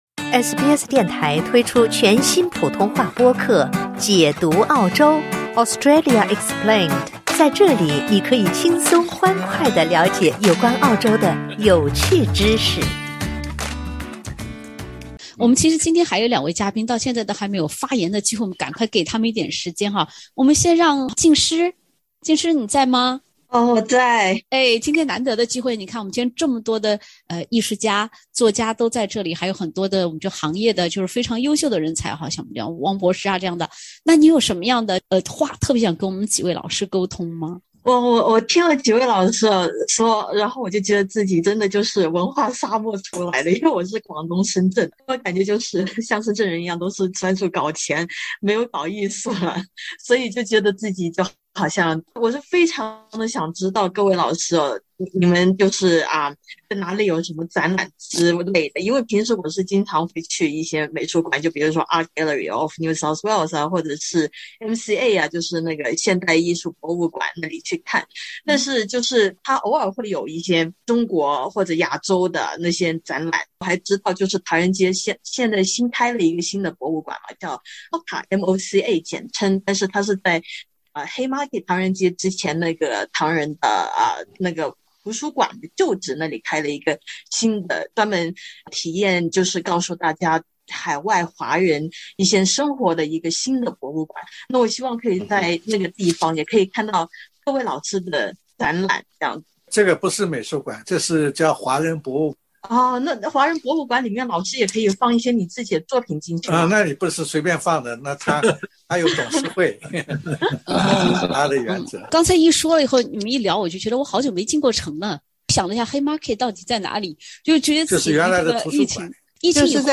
SBS全新谈话类节目《对话后浪》，倾听普通人的烦恼，了解普通人的欢乐，走进普通人的生活。
（点击封面图片，收听风趣对话）